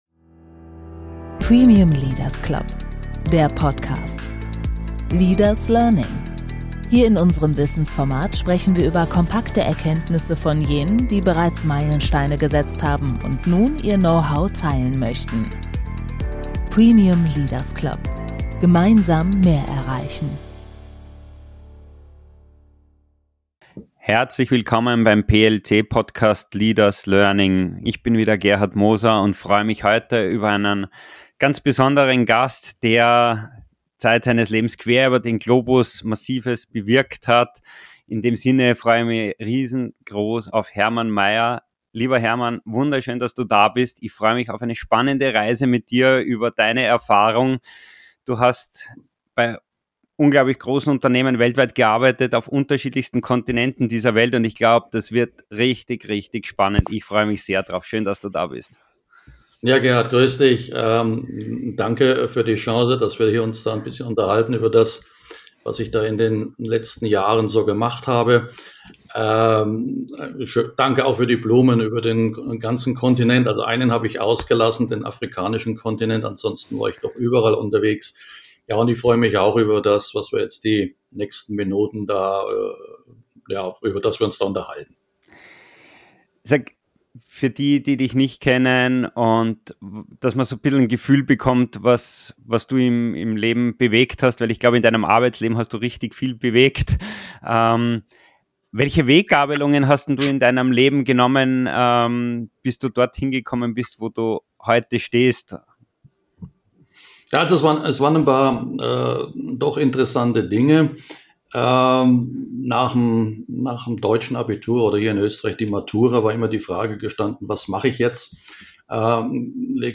Ein inspirierender Talk für alle, die Führung neu denken, internationale Teams managen oder einfach vom Erfahrungsschatz einer außergewöhnlichen Karriere lernen möchten!